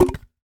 Sfx Nerf Gun Impact Sound Effect
Download a high-quality sfx nerf gun impact sound effect.
sfx-nerf-gun-impact.mp3